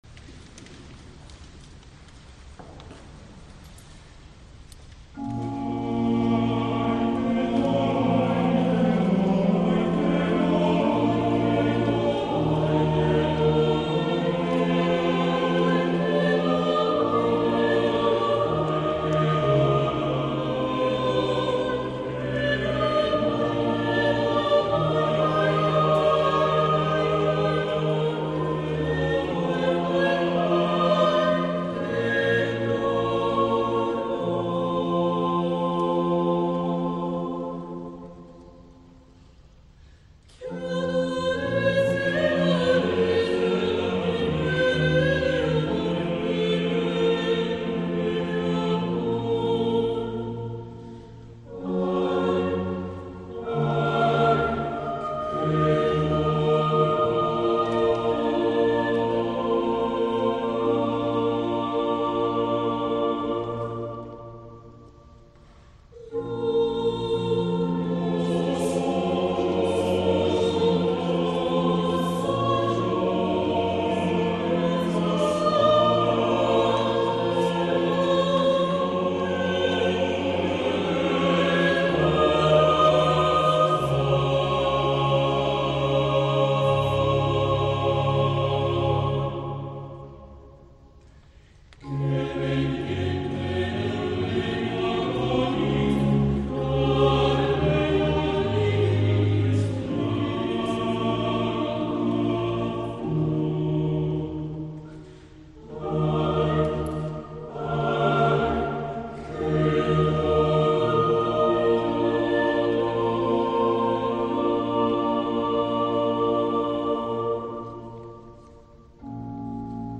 La Stagione Armonica
Abans d’ahir va tenir lloc a Torroella de Montgrí i dins el marc de la 31 edició del Festival de Músiques, un concert coral magnífic i que Catalunya Música va retransmetre en directa.
El programa, celebrat a l’Església de Sant Genís, estava integrat de les següents obres, interpretades sense solució de continuïtat:
Villancico “Ay qué dolor” a 5 veus
Baix continuo